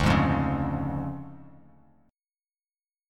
DmM7b5 chord